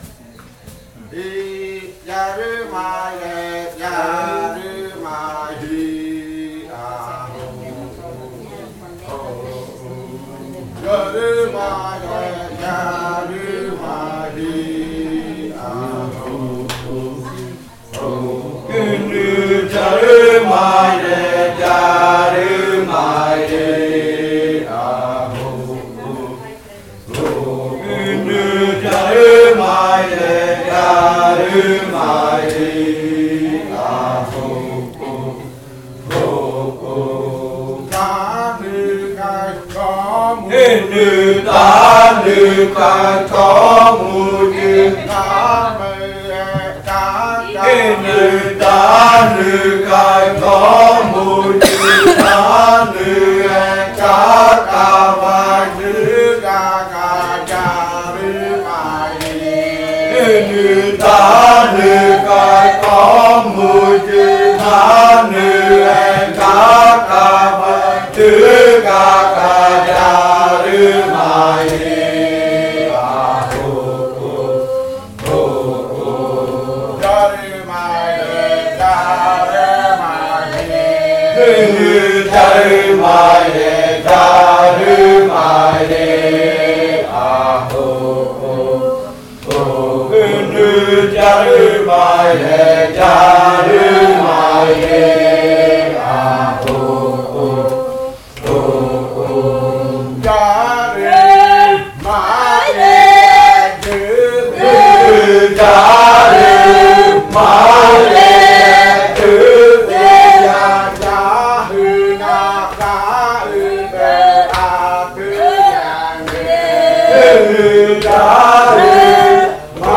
Canto de la variante jimokɨ
Leticia, Amazonas
con el grupo de cantores bailando en Nokaido.
with the group of singers dancing in Nokaido. This song is part of the collection of songs from the yuakɨ murui-muina ritual (fruit ritual) of the Murui people, a collection that was compiled by the Kaɨ Komuiya Uai Dance Group with support from UNAL, Amazonia campus.